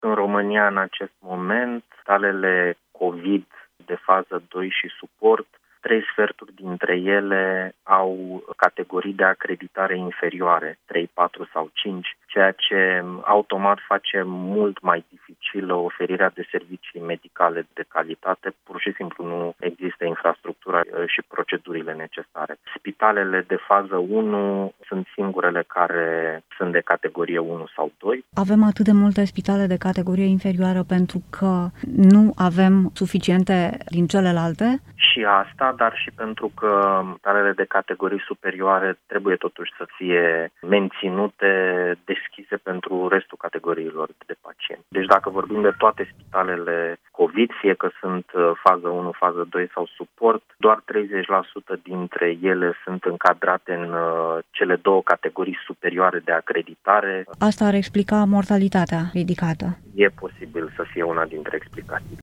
în dialog cu